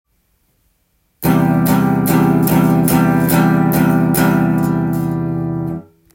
まずは悪い音がする5弦３フレットのパワーコード弾いてみました
不協和音のような低音が鳴り響き３弦や２弦の開放弦の音も聞こえます。
綺麗とは言えないパワーコードです。